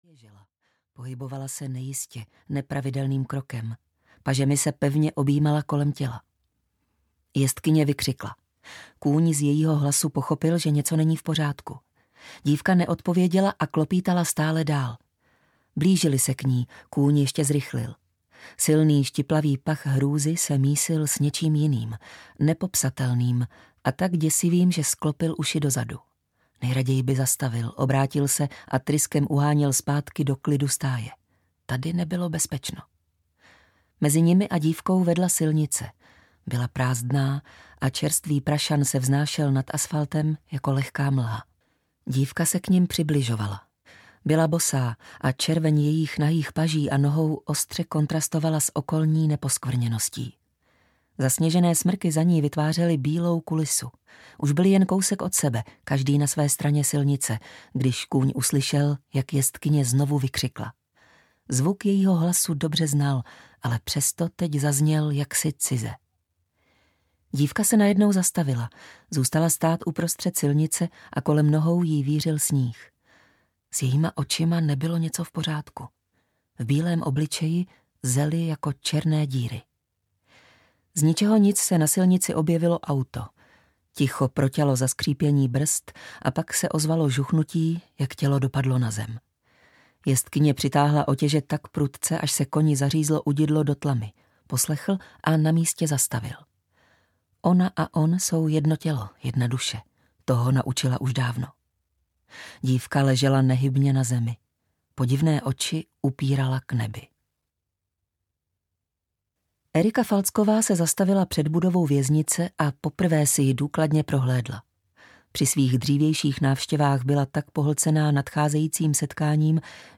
Krotitel audiokniha
Ukázka z knihy